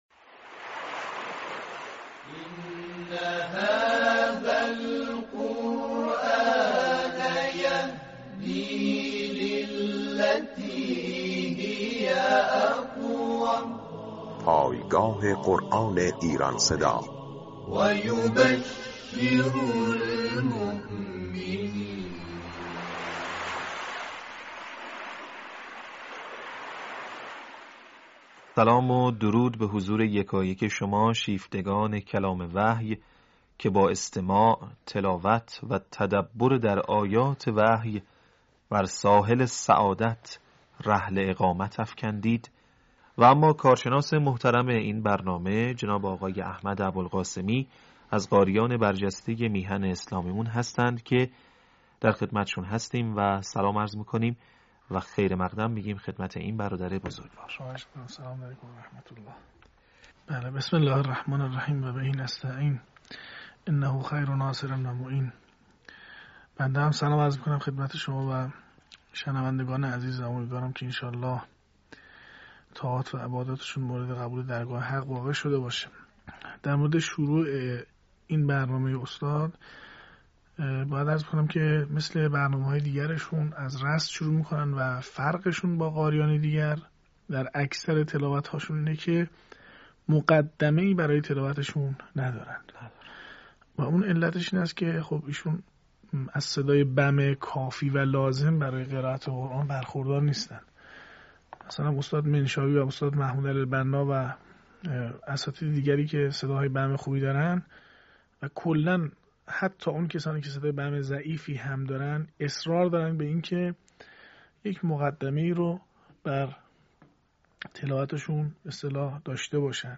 تحلیل تلاوت محمد عبدالعزیز حصان